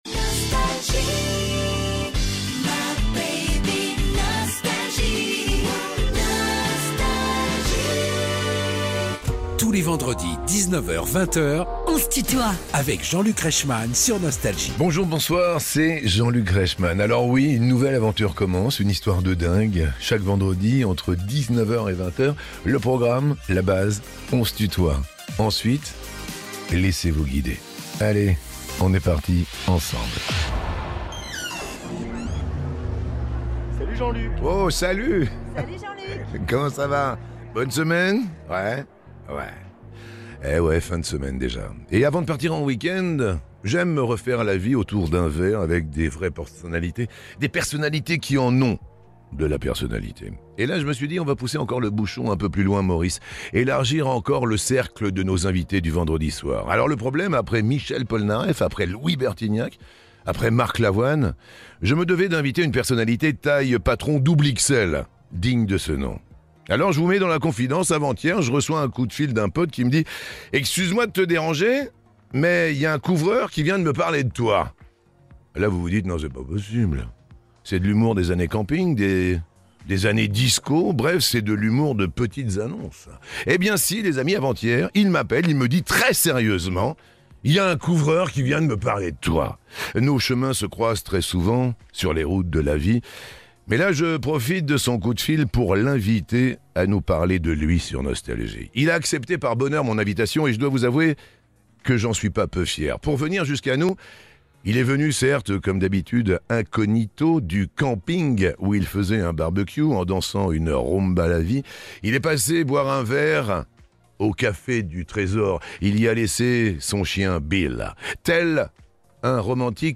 Il est venu en parler à Jean-luc Reichmann dans "On se tutoie ?..."